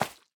Minecraft Version Minecraft Version snapshot Latest Release | Latest Snapshot snapshot / assets / minecraft / sounds / block / cake / add_candle3.ogg Compare With Compare With Latest Release | Latest Snapshot
add_candle3.ogg